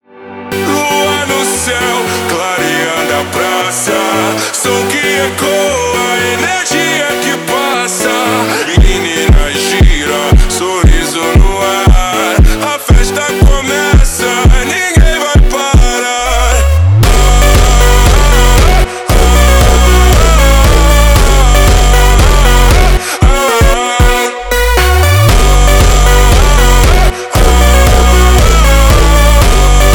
Electronic
Жанр: Электроника